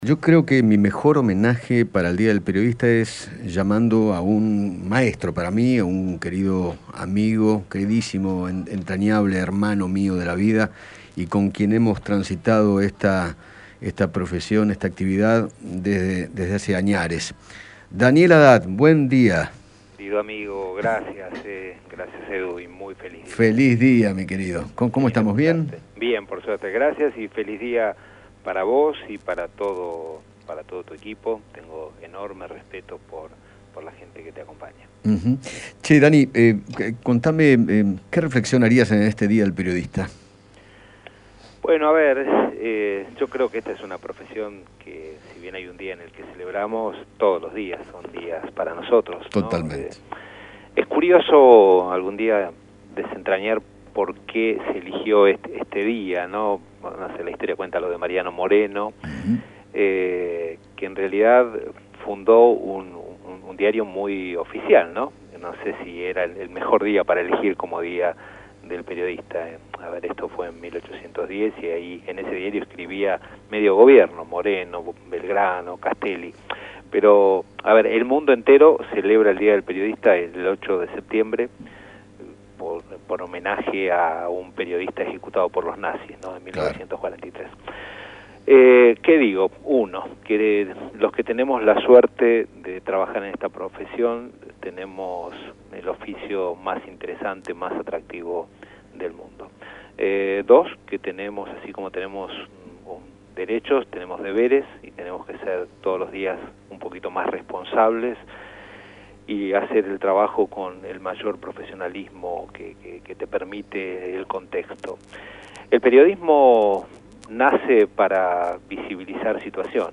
Daniel Hadad, periodista y empresario, habló con Eduardo Feinmann sobre el Día del Periodista y reflexionó acerca de la profesión.